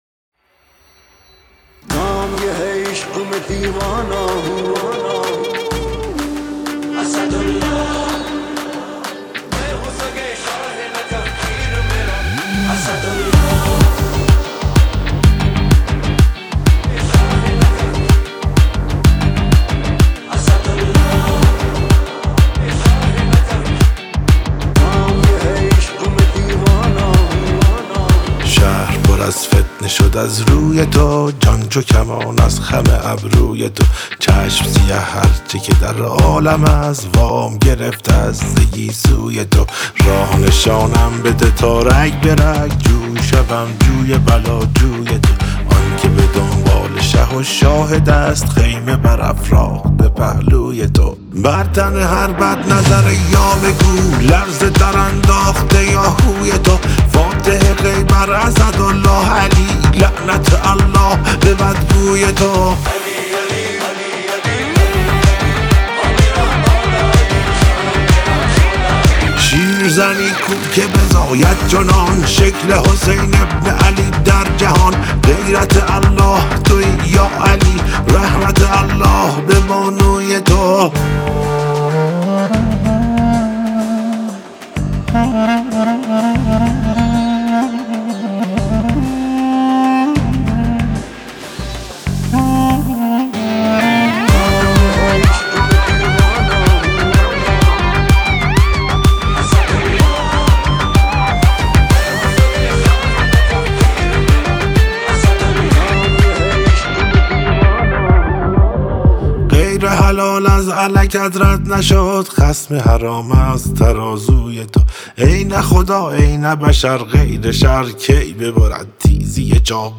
با صدای گرم